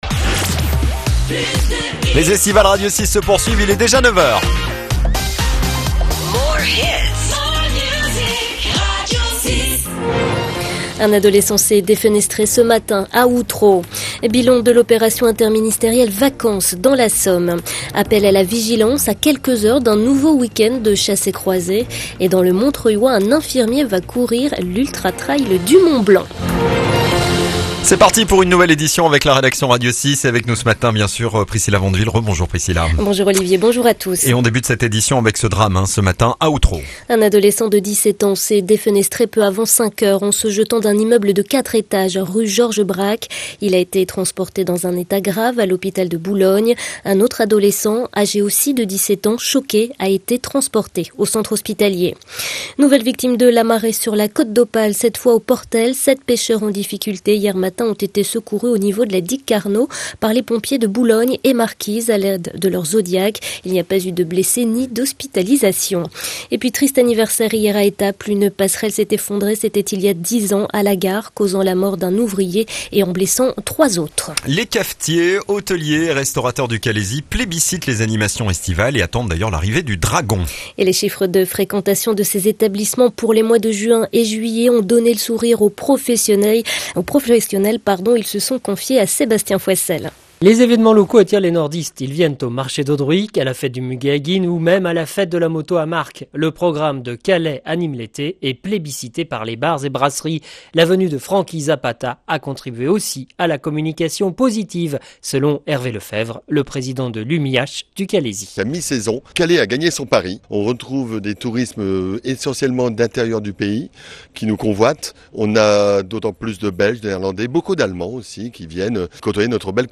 Le journal de 9h du vendredi 16 août 2019